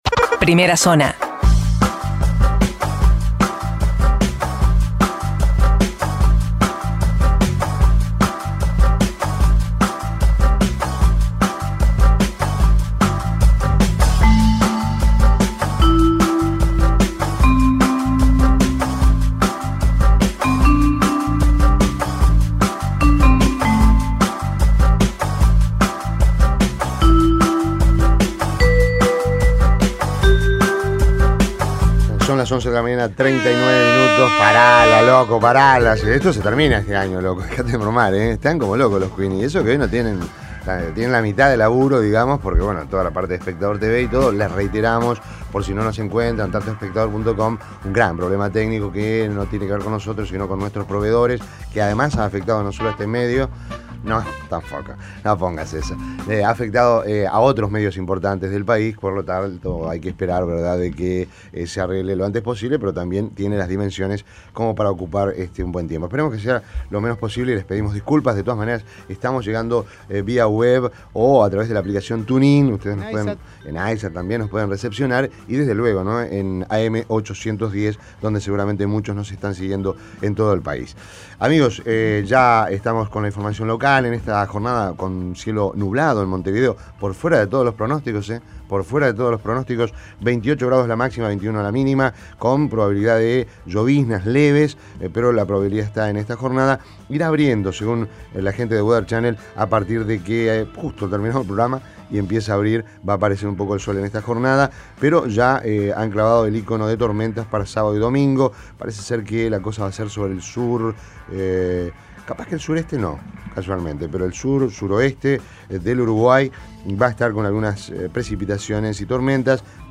resumen de noticias Primera Zona Imprimir A- A A+ Las principales noticias del día, resumidas en la Primera Zona de Rompkbzas.